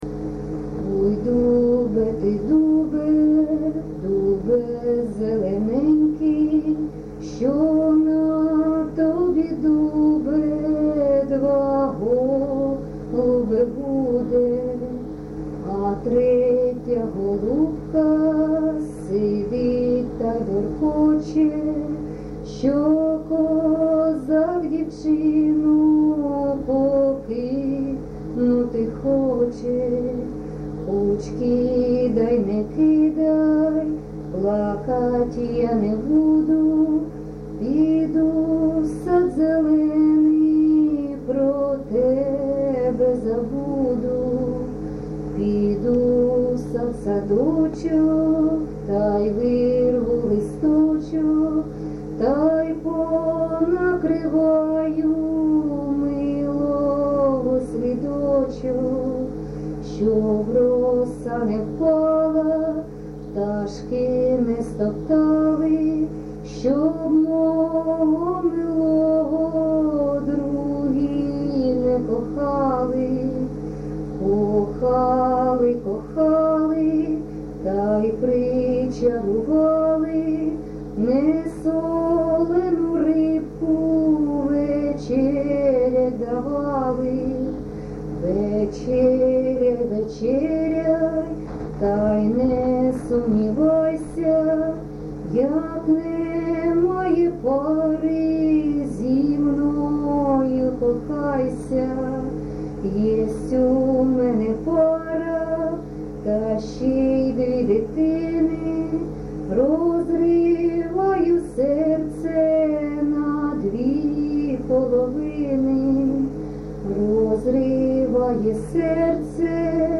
ЖанрПісні з особистого та родинного життя
Місце записус. Келеберда, Кременчуцький район, Полтавська обл., Україна, Полтавщина